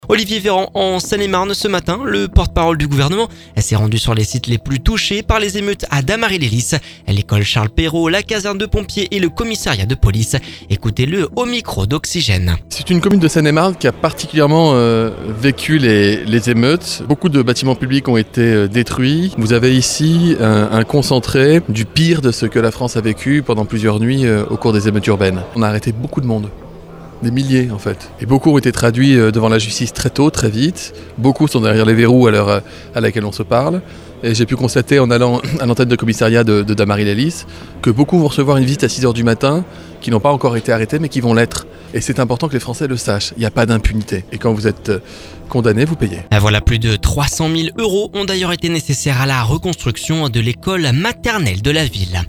Ecoutez-le au micro d’Oxygène…Plus de 300 000 euros ont été nécessaires à la reconstruction de l’école maternelle de la ville !